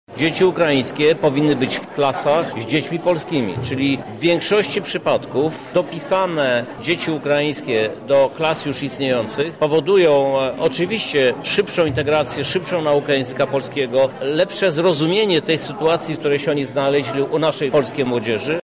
Ten bardzo duży wysiłek edukacyjny, którego się podjęliśmy jest oparty na dobrym założeniu – mówi Krzysztof Żuk, prezydent Lublina: